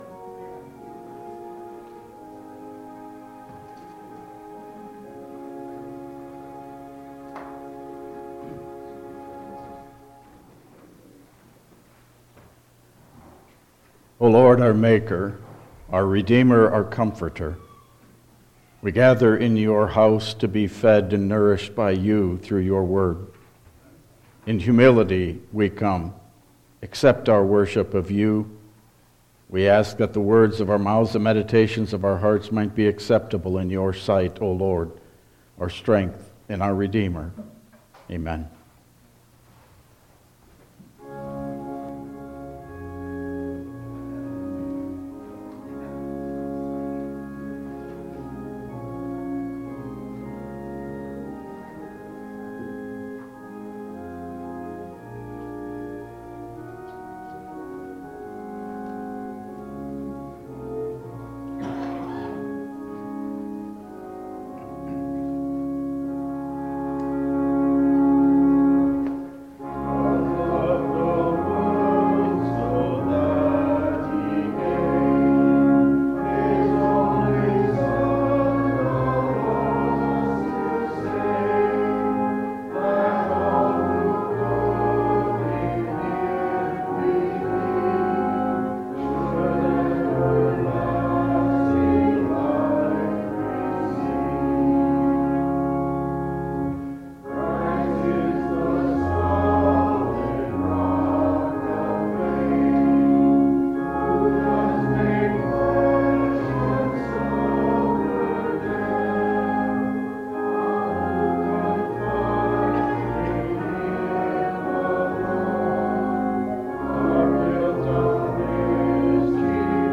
Download Files Printed Sermon and Bulletin
Service Type: Regular Service